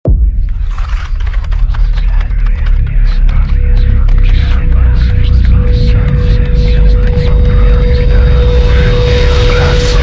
Звук коллапса теневой аномалии